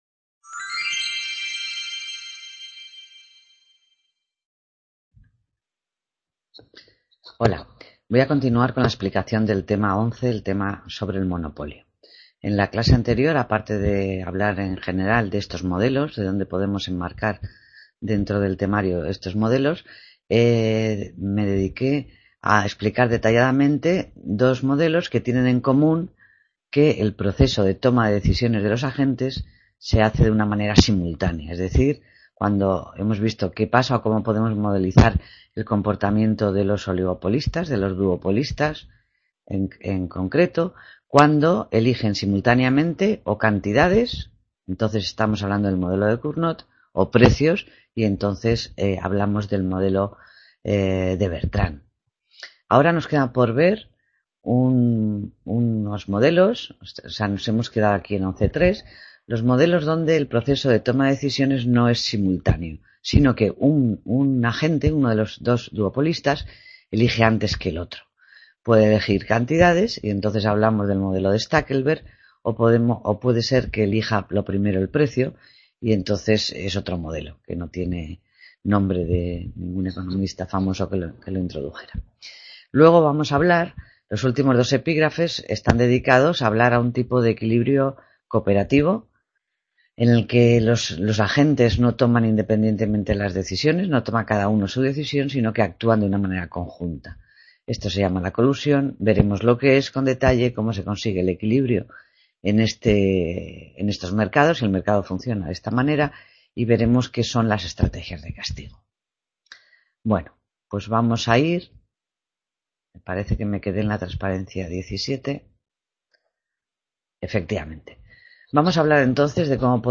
Clase